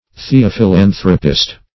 Search Result for " theophilanthropist" : The Collaborative International Dictionary of English v.0.48: Theophilanthropist \The`o*phi*lan"thro*pist\, n. [Cf. F. th['e]ophilanthrope.]
theophilanthropist.mp3